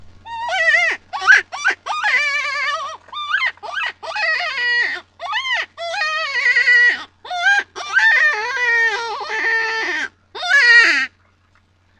Nordik Mini Predator ist eine Fuchs Pfeife, mit der Maulwurf- und Mäusegeräusche sowie das Notsignal von Hasen leicht nachgeahmt werden kann.
Die Pfeife ist nicht voreingestellt, die Tonhöhe wird über die Zähne geregelt.